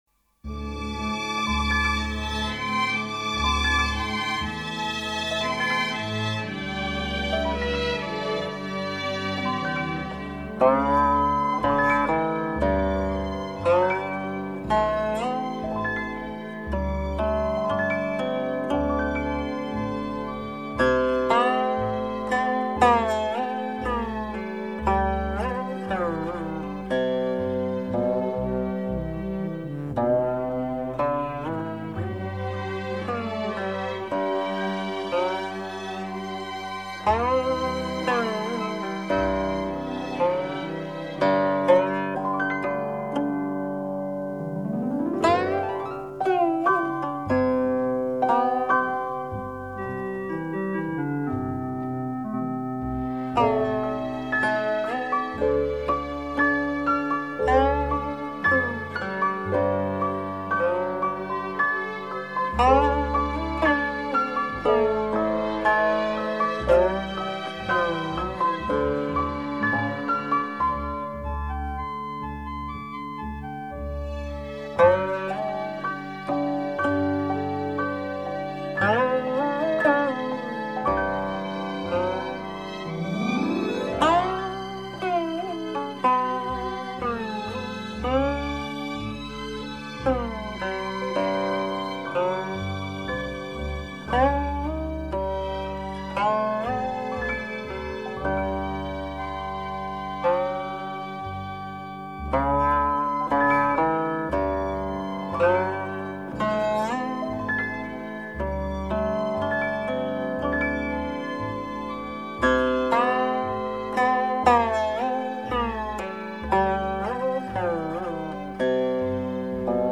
Kínai népzene